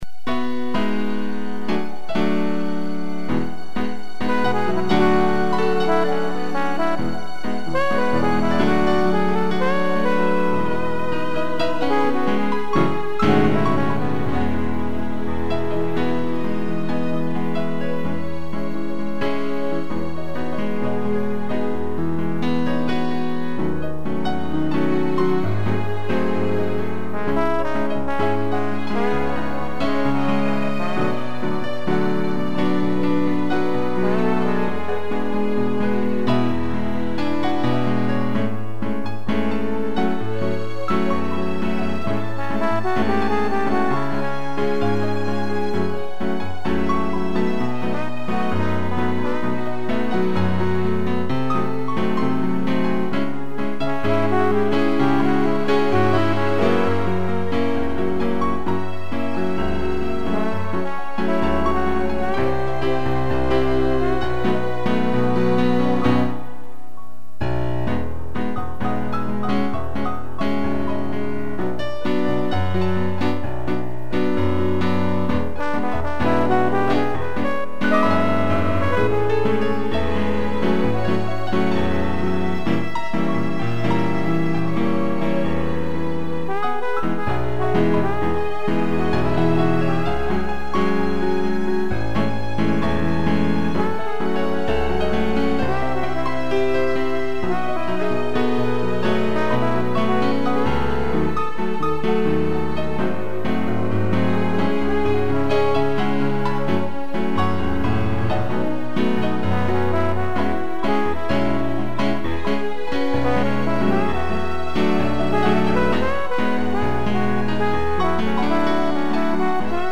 2 pianos, trombone e strings
(instrumental)